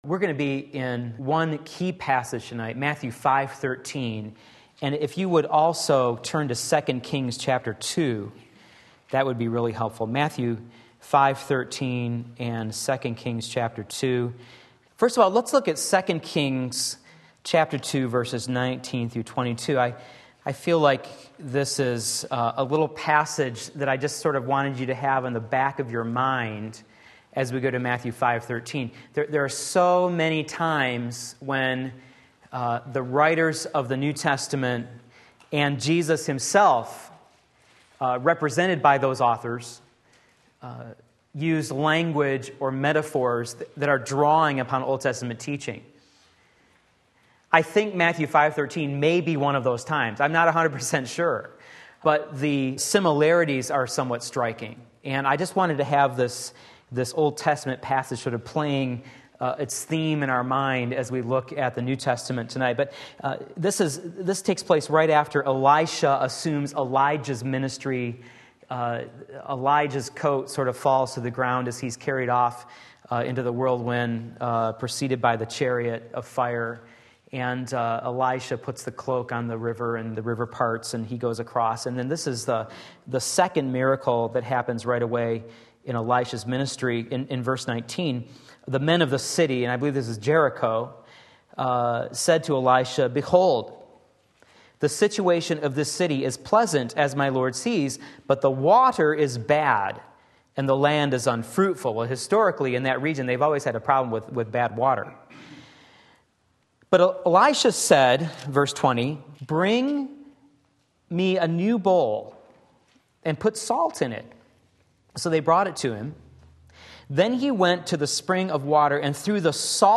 Sermon Link
2 Kings 2:19-22 Wednesday Evening Service